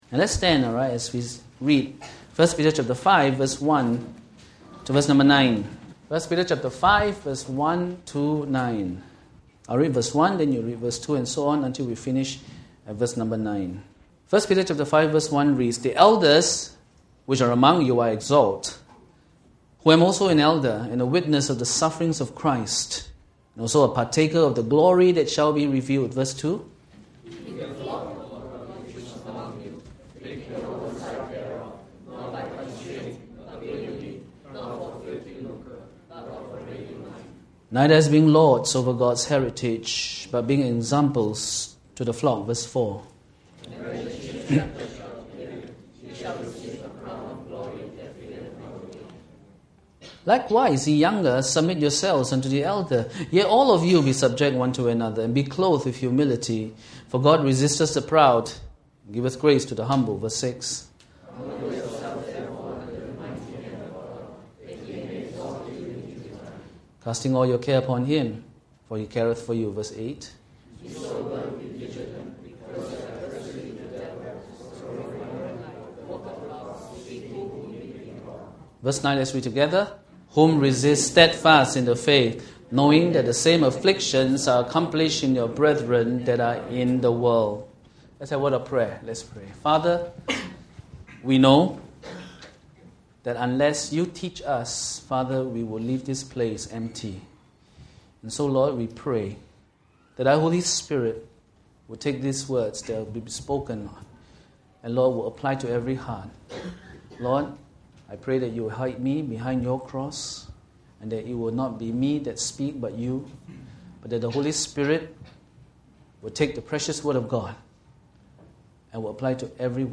Sunday Worship Service